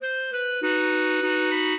minuet8-9.wav